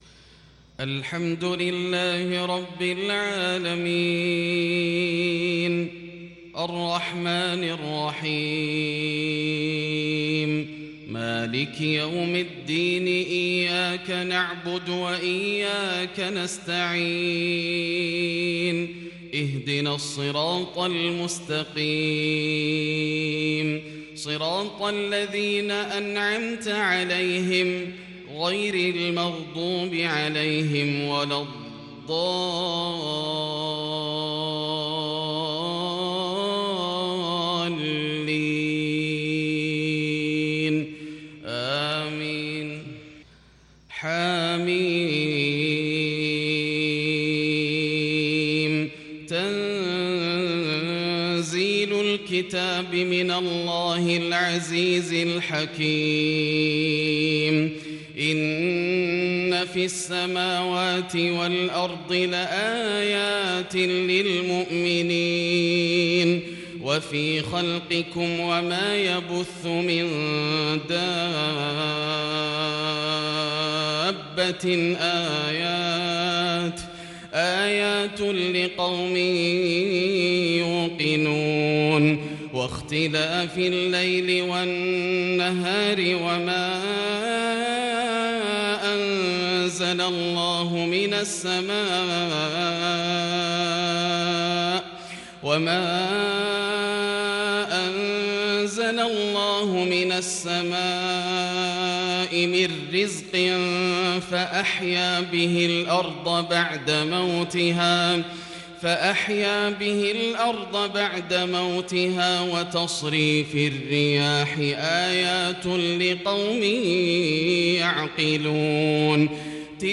صلاة الفجر للشيخ ياسر الدوسري 21 صفر 1442 هـ
تِلَاوَات الْحَرَمَيْن .